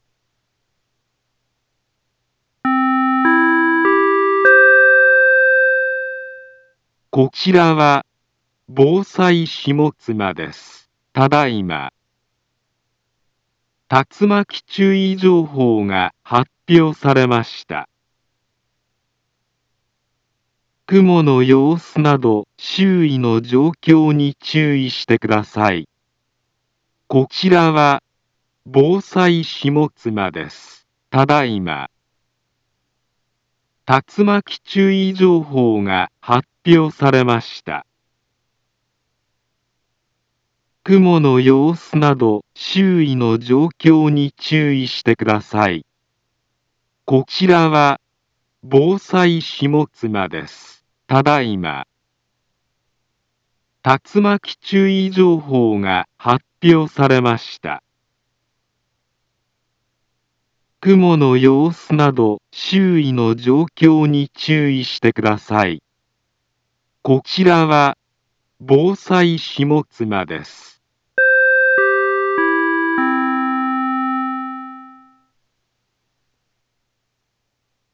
Back Home Ｊアラート情報 音声放送 再生 災害情報 カテゴリ：J-ALERT 登録日時：2023-07-12 17:29:49 インフォメーション：茨城県北部、南部は、竜巻などの激しい突風が発生しやすい気象状況になっています。